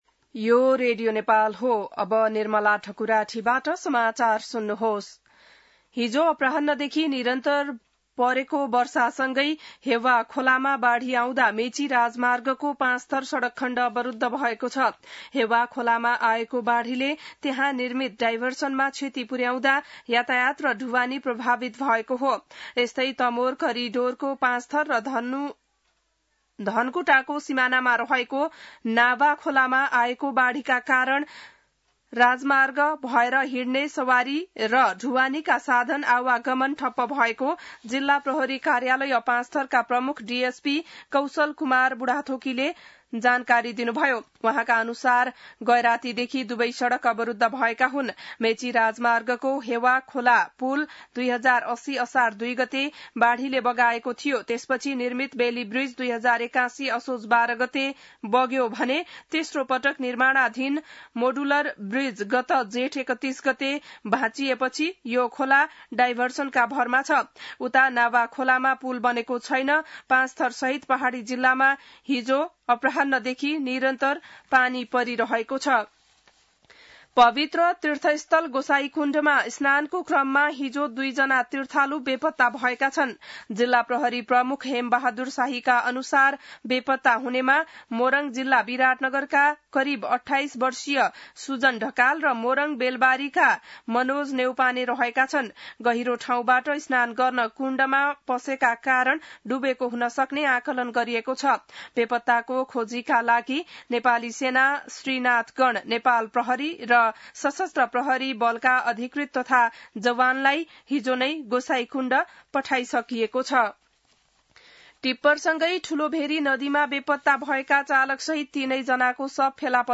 बिहान १० बजेको नेपाली समाचार : १२ साउन , २०८२